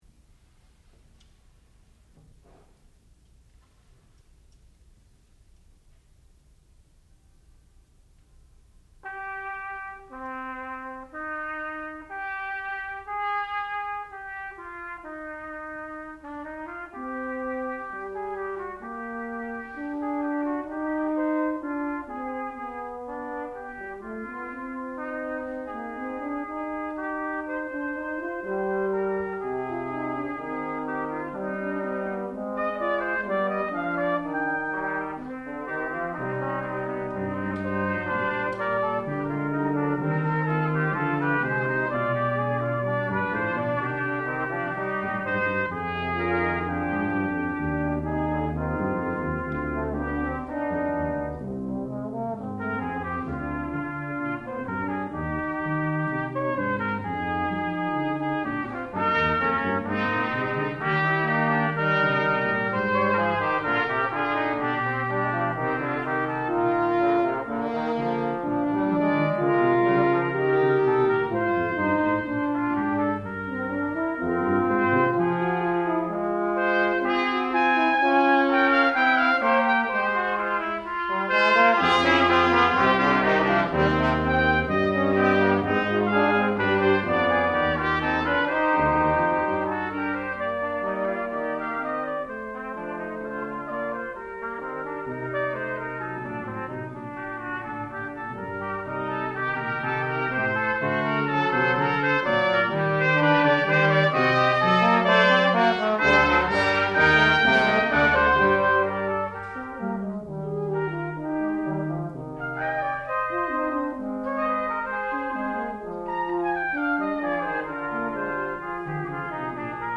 sound recording-musical
classical music
trumpet
trombone